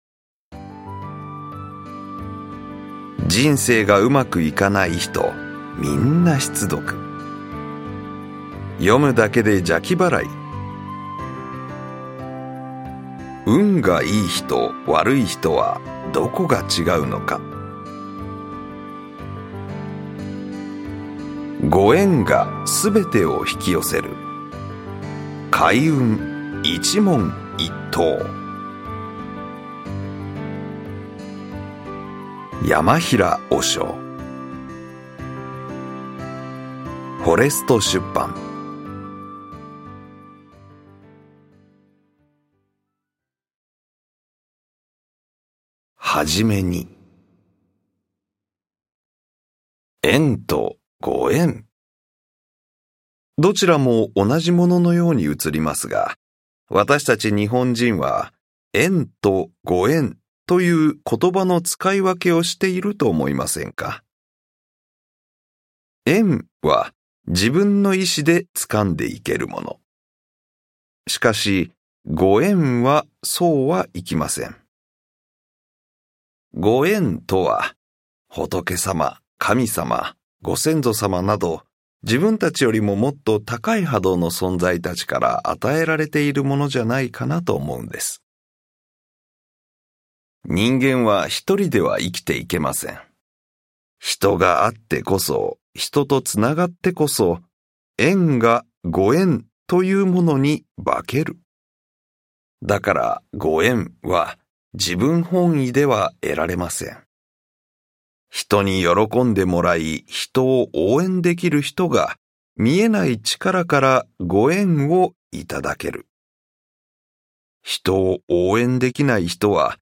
[オーディオブック] 「ご縁」がすべてを引き寄せる開運一問一答